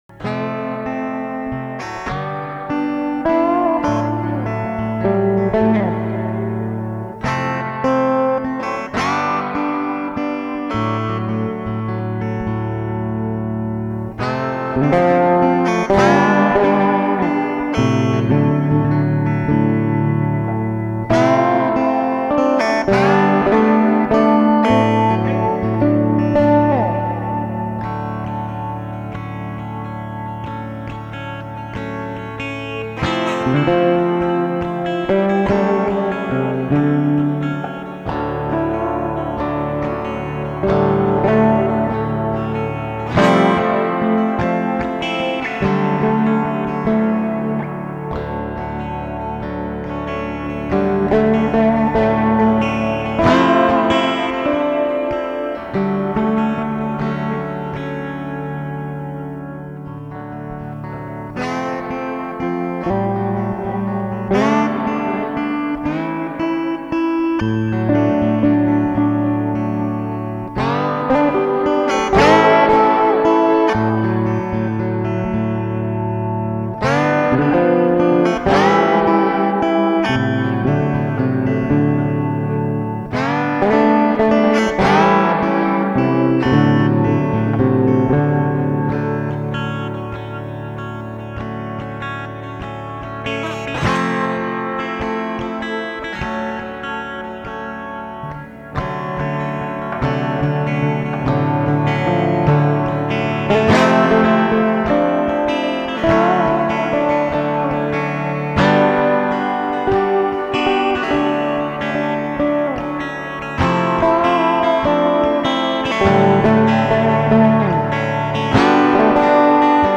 Steelguitar with Cigarbox Guitar.